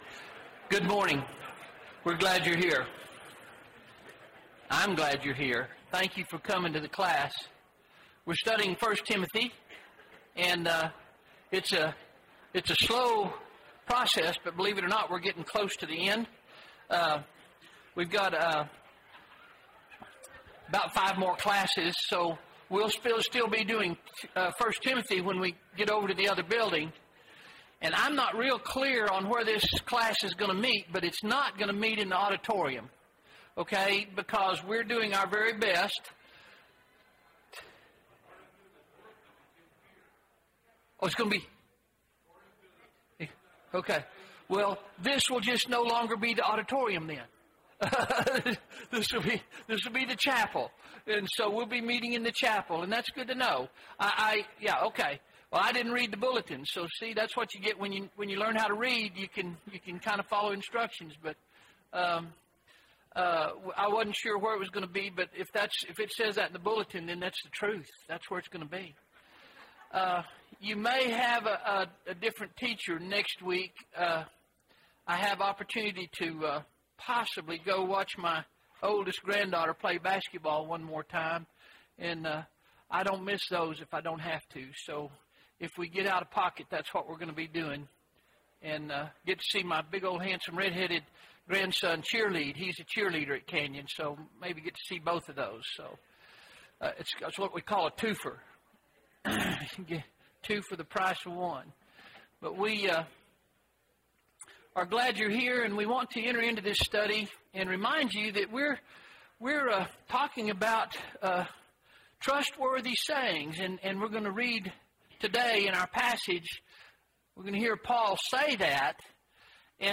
Sunday AM Bible Class